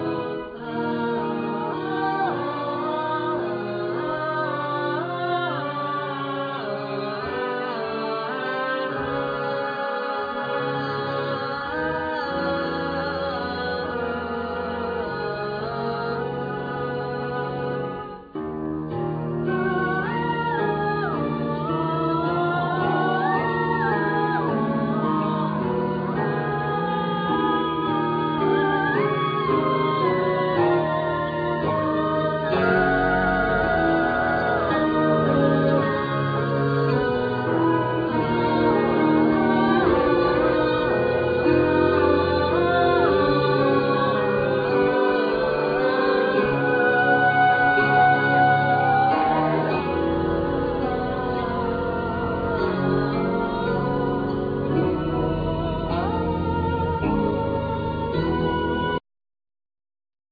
Keyboards
Voices
Saxophones
Recorder flute